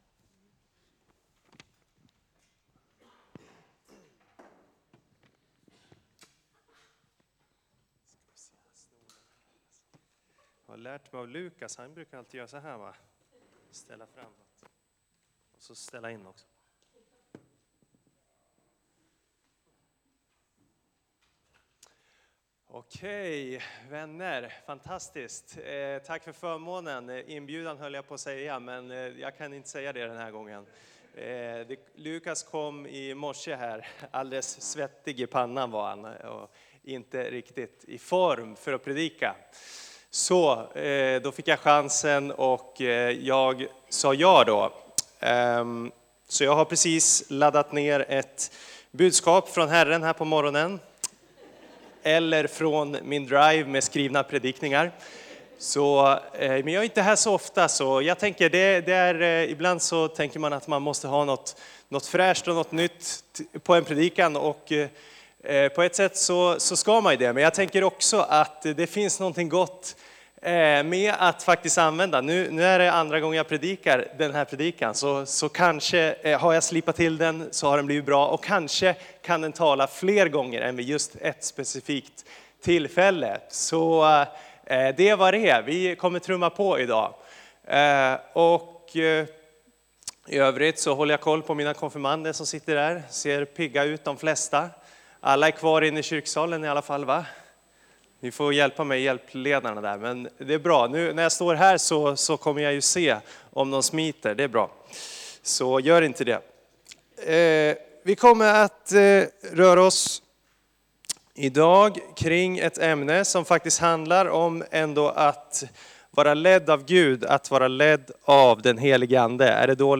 Gudstjänst 30:e mars